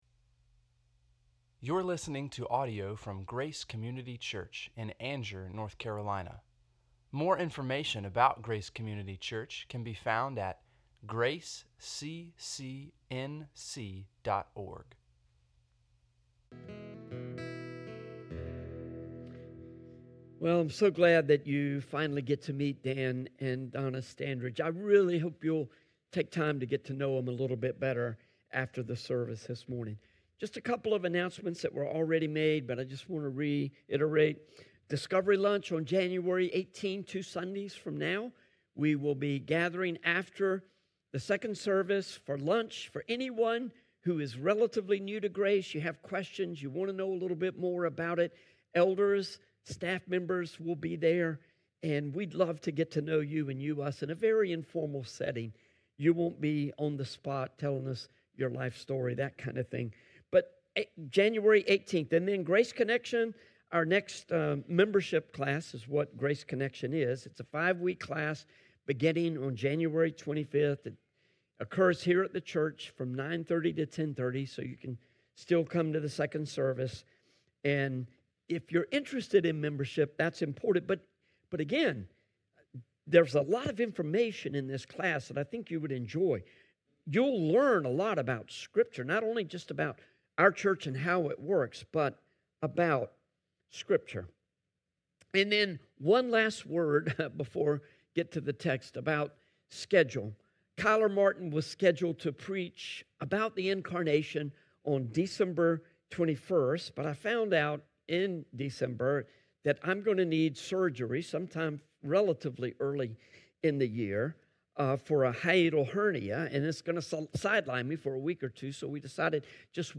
sermon-1-4-26.mp3